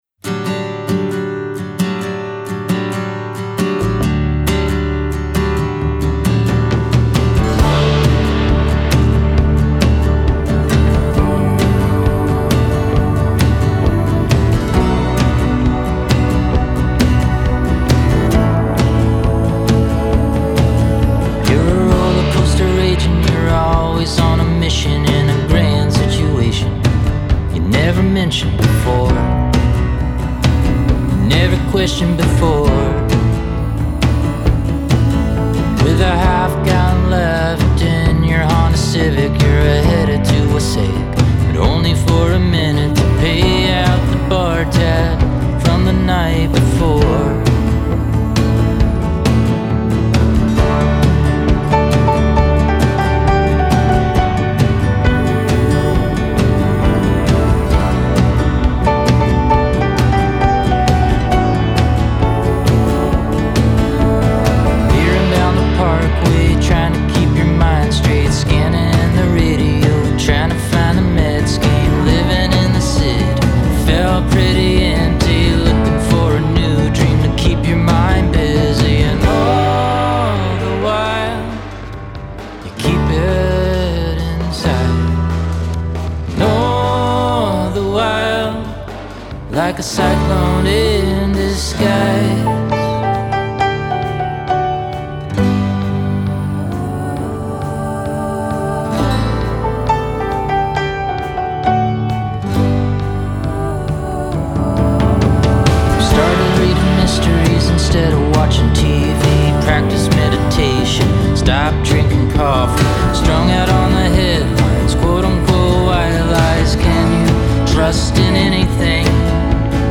NPR - “Big-Hearted, Rootsy”
Keys
Guitars, Vocals
Violin